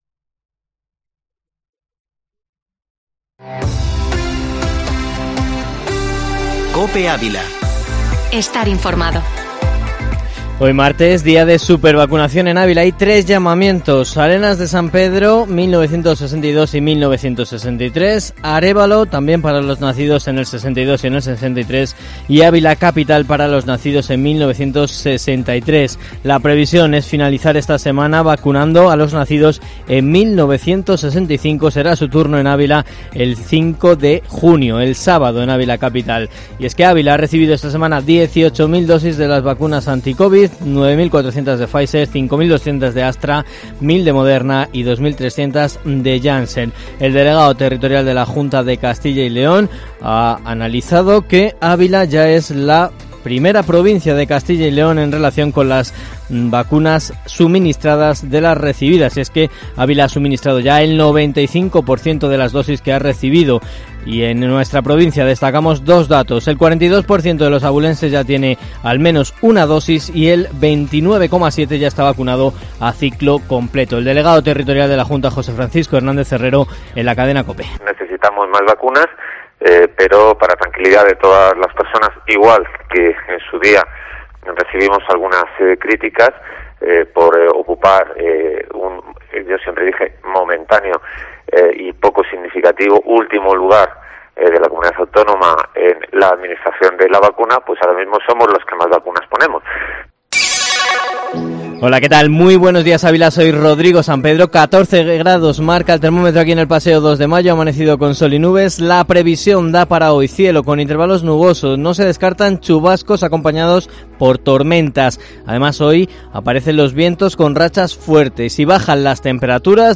Informativo Matinal Herrera en COPE Ávila 01/06/2021